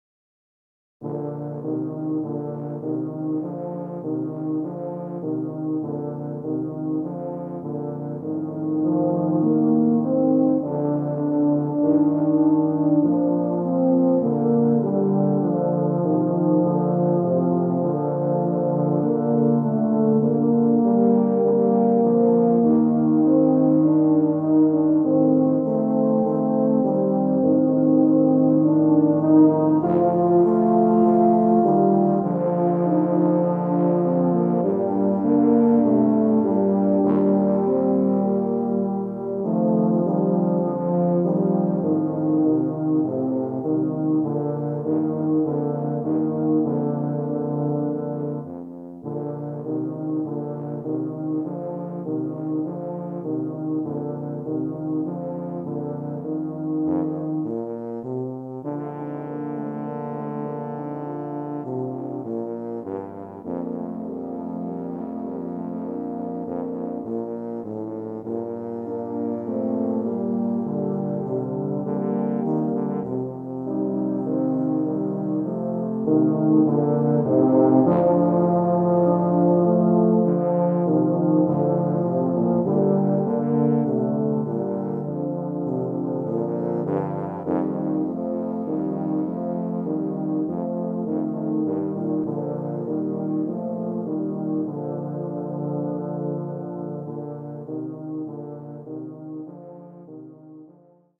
Composer: Scottish Folk Song
Voicing: Tuba Quartet (EETT)